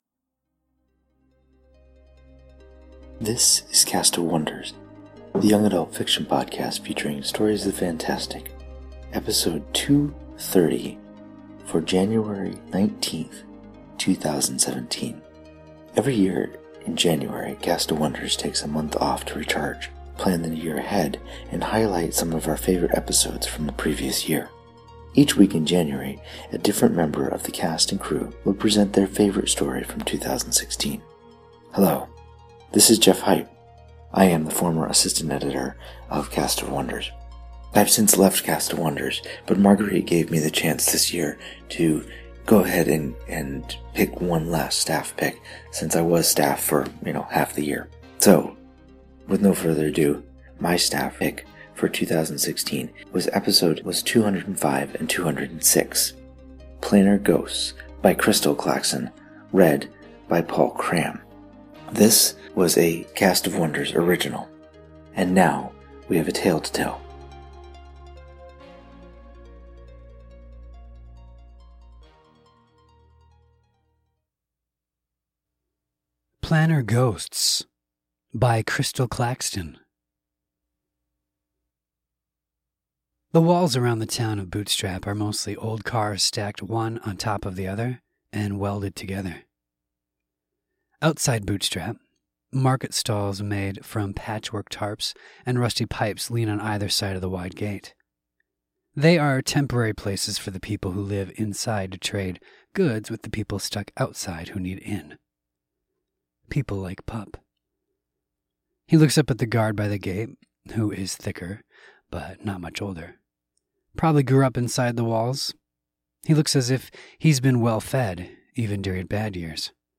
Audio-Narration